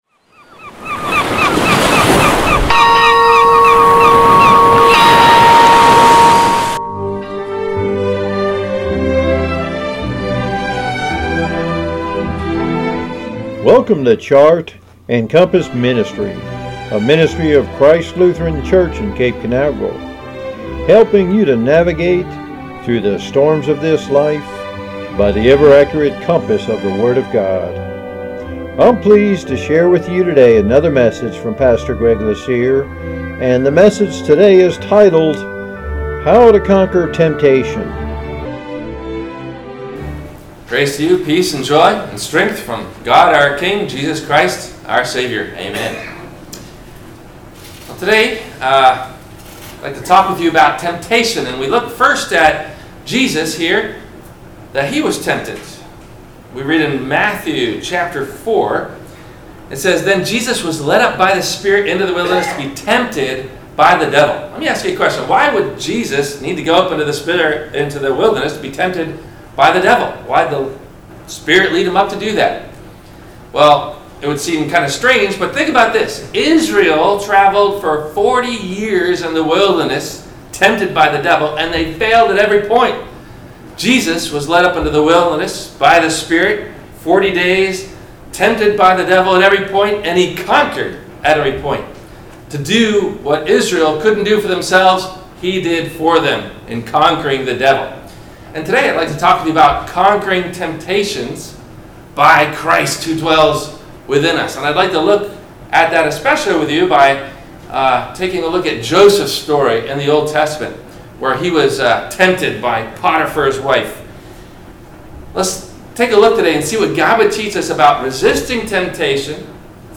No Questions asked before the Message.